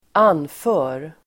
Uttal: [²'an:fö:r]